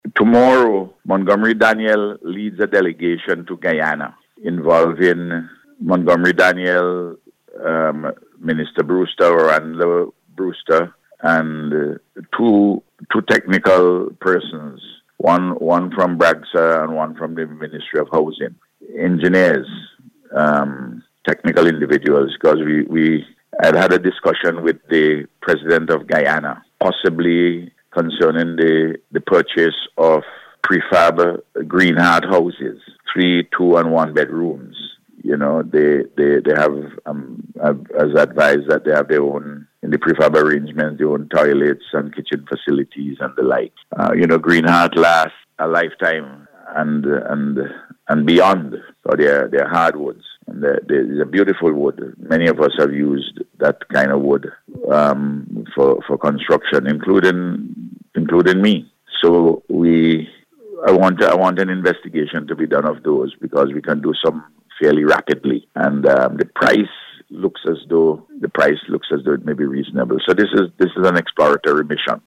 Speaking from Montserrat on NBC’s Face to Face programme this morning, the Prime Minister said he is scheduled to return to the state on Saturday morning.